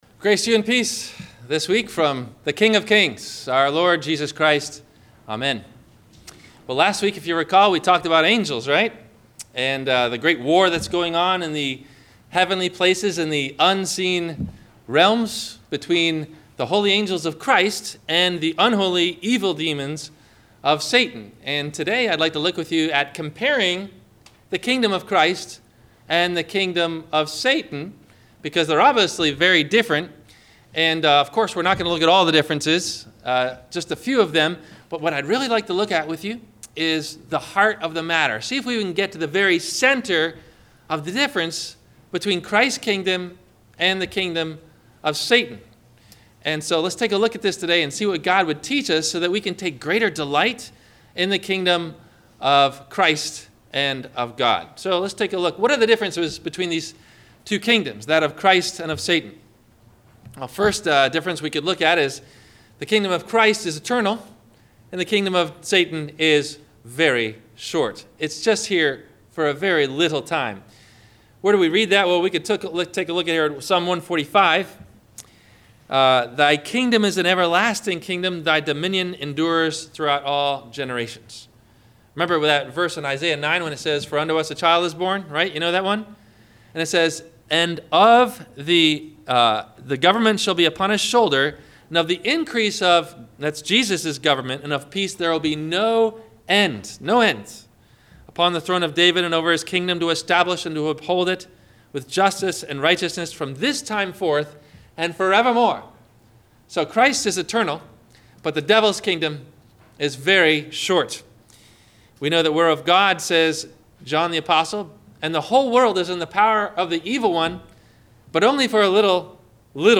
The Kingdom of Self vs The Kingdom of Christ - Sermon - June 05 2016 - Christ Lutheran Cape Canaveral